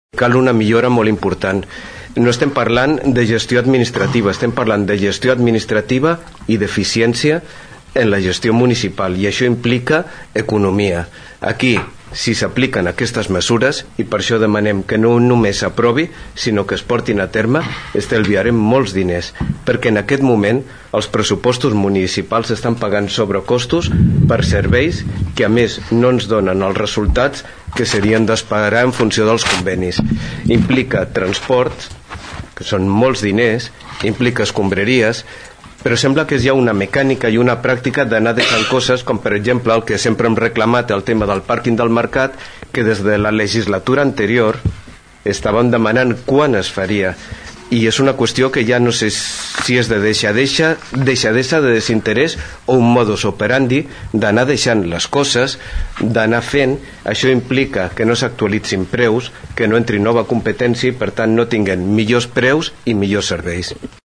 El regidor del PSC, Rafa Delgado, també va agrair el treball del regidor popular. Va destacar la importància de millorar la gestió per aconseguir un estalvi.